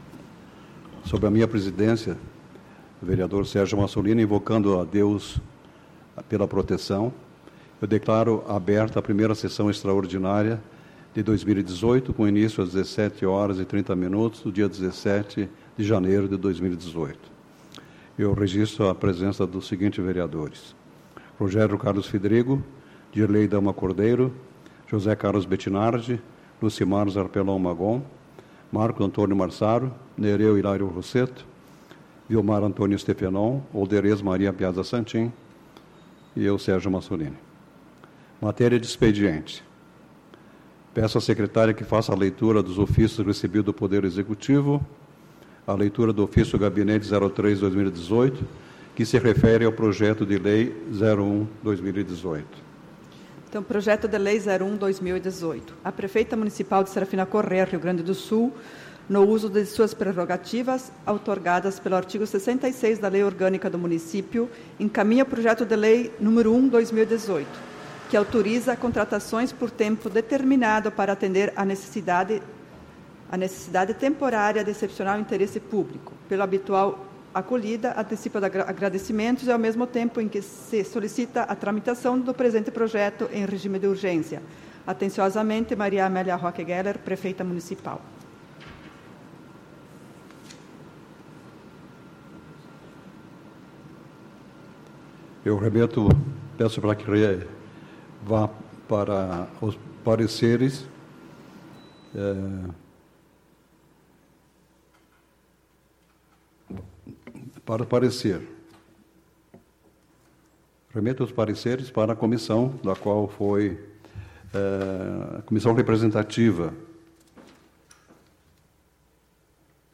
Tipo de Sessão: Extraordinária